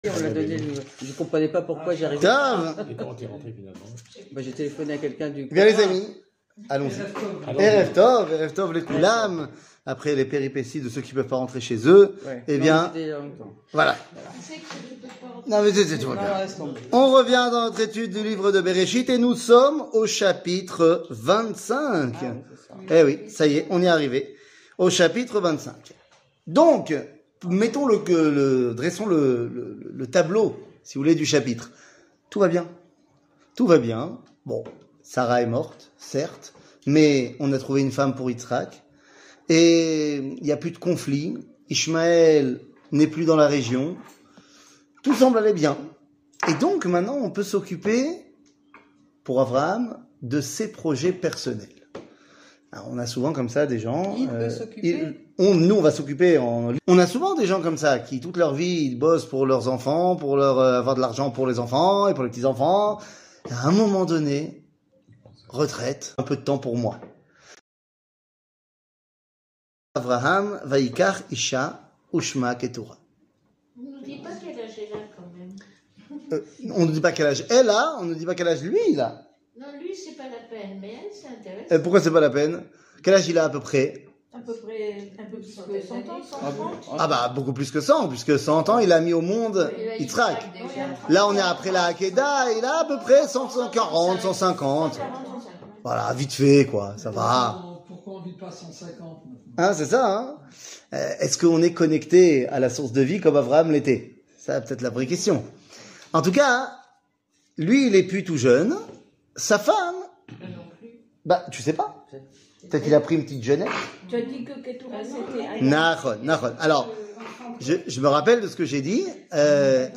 קטגוריה t 00:44:58 t שיעור מ 01 יוני 2022 44MIN הורדה בקובץ אודיו MP3